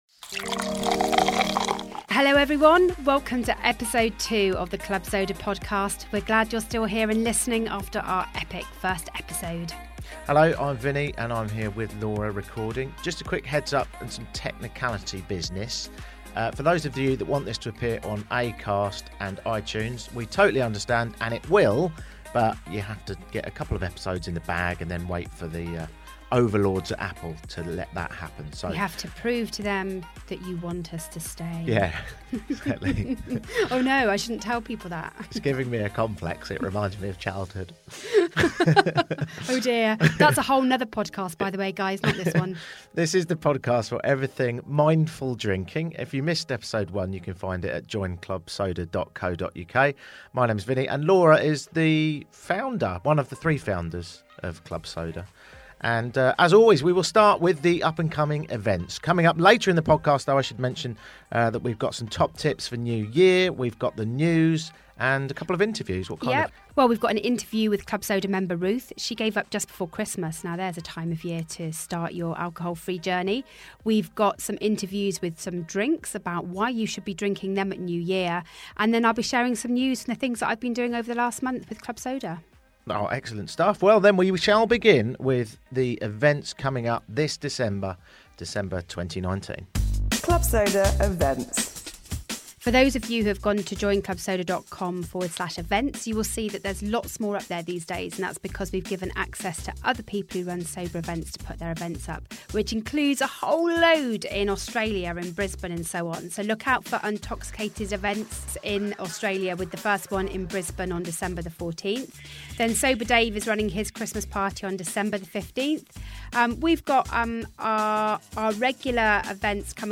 Club Soda podcast episode 2 is full of great things to get you through a cold, dark winter as a mindful drinker: drink ideas, stories, expert interviews.